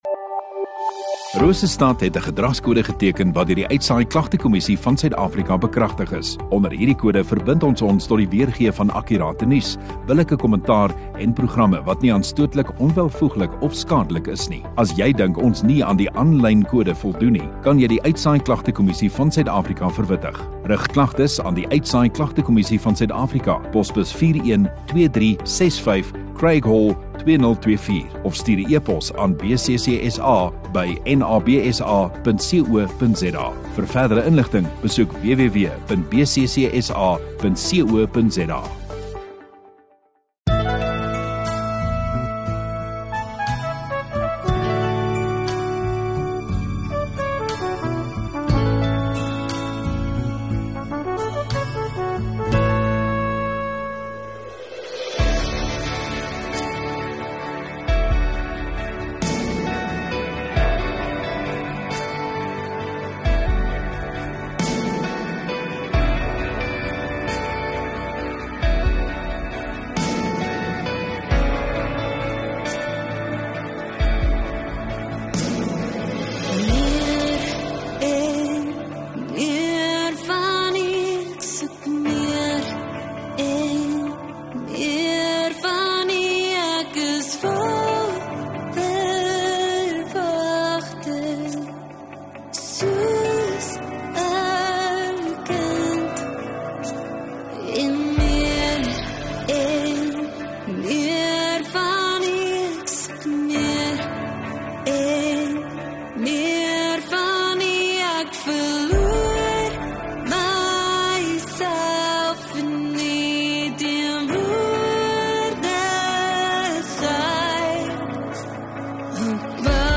11 Dec Woensdag Oggenddiens